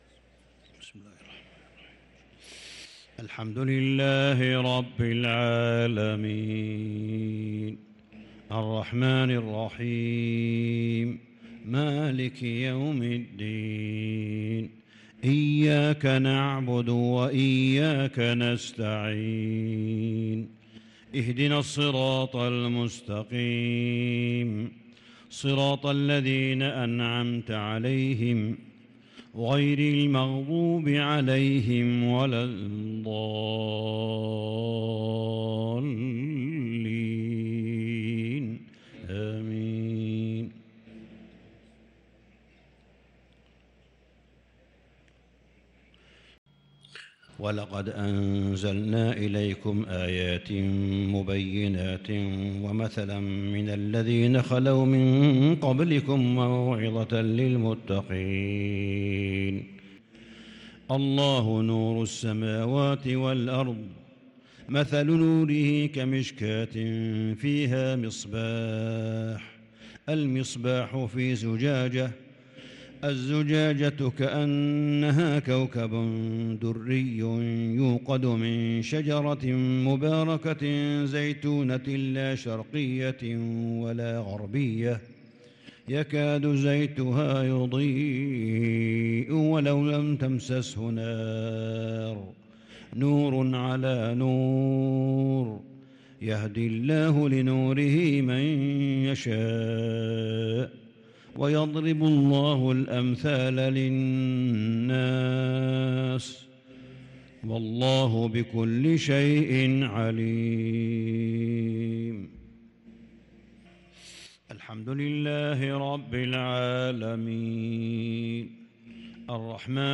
عشاء الأربعاء 5 رمضان 1443هـ من سورة النور | Isha prayer from Surah An-Nur 6-4-2022 > 1443 🕋 > الفروض - تلاوات الحرمين